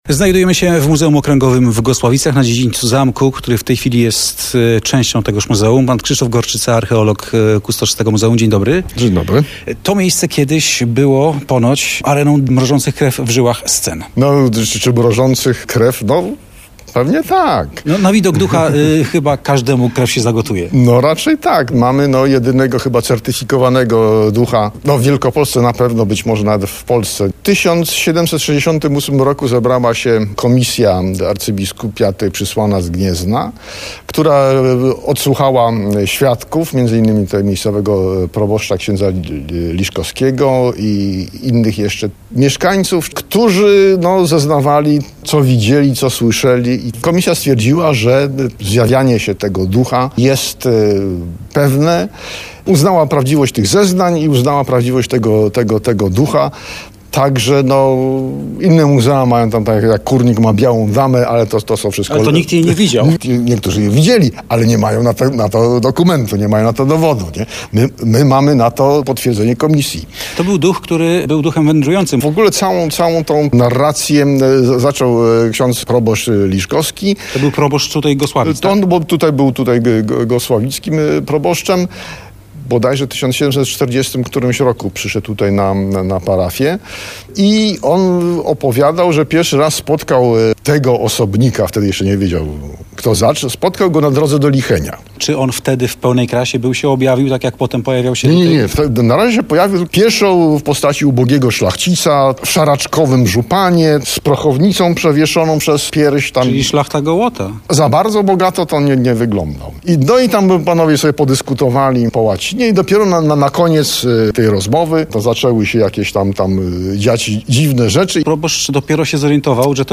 Duch w wielkopolskim muzeum - Radio Poznań